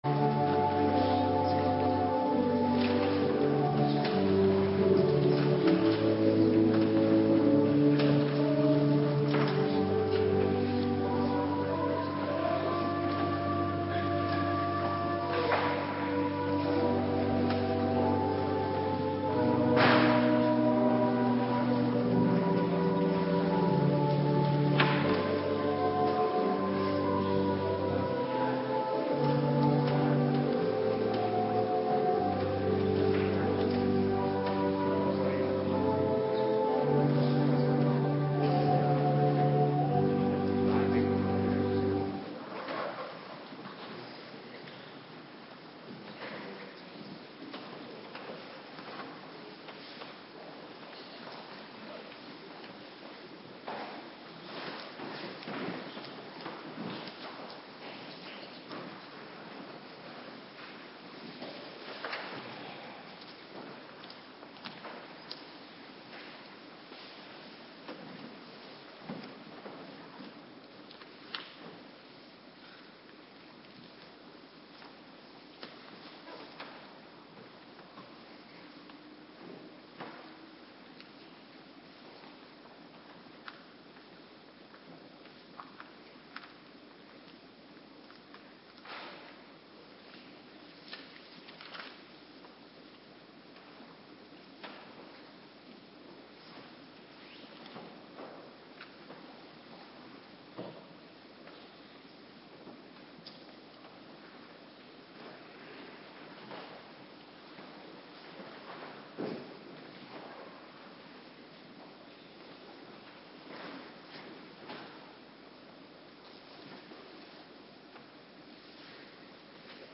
Locatie: Hervormde Gemeente Waarder